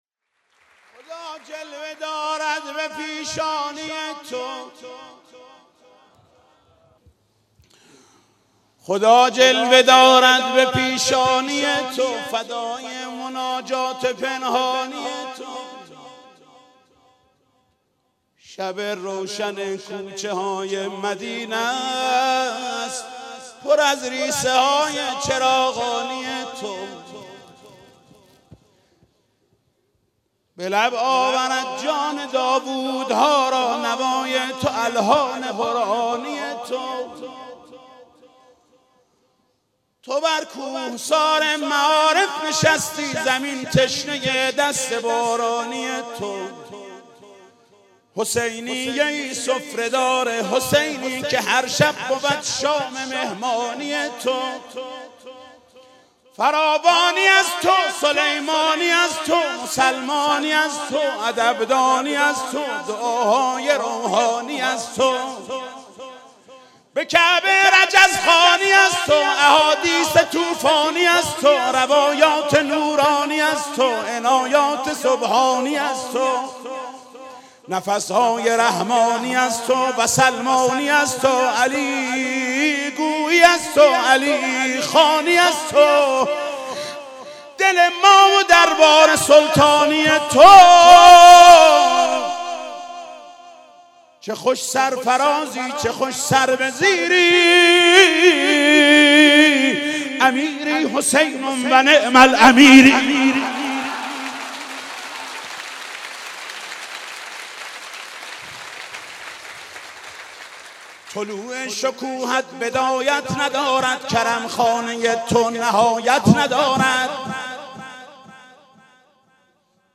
خبرگزاری تسنیم: حاج محمود کریمی شب گذشته غزلی زیبا در وصف علمدار کربلا خواند.